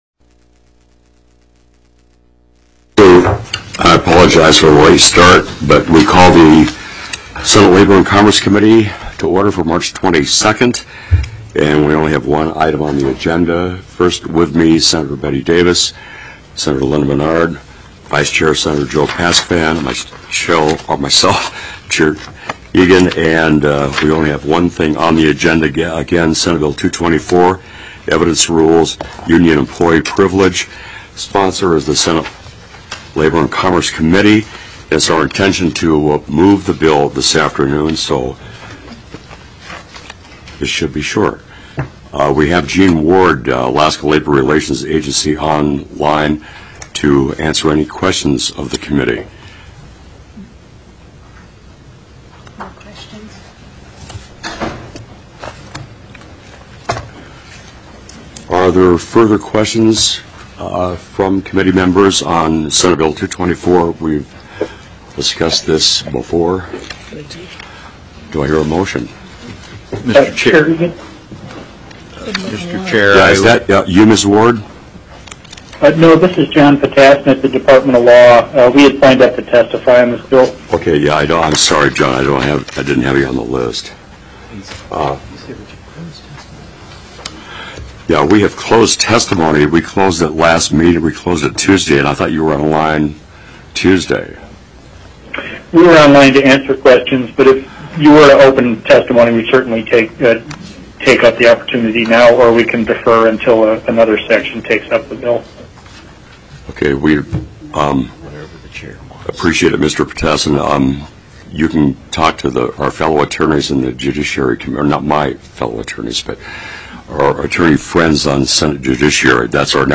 03/22/2012 01:30 PM Senate LABOR & COMMERCE
+= SB 224 EVIDENCE RULES: UNION/EMPLOYEE PRIVILEGE TELECONFERENCED
Senator Dennis Egan, Chair